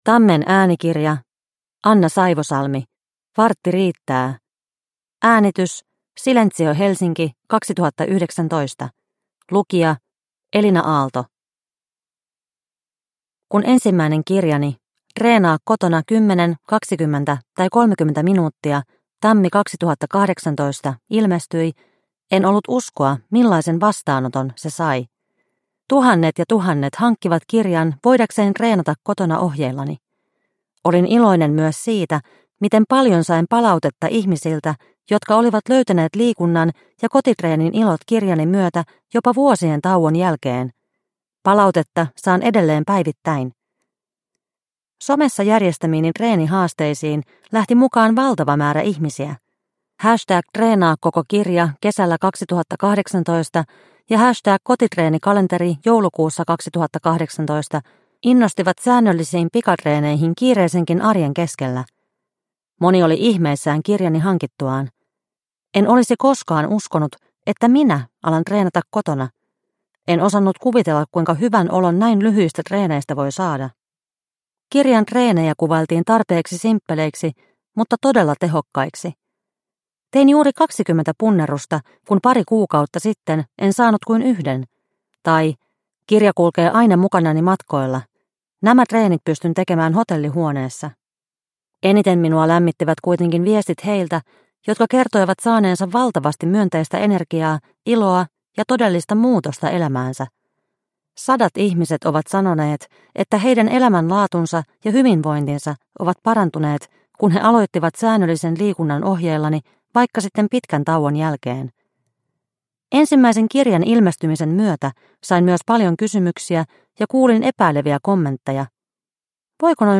Vartti riittää – Ljudbok – Laddas ner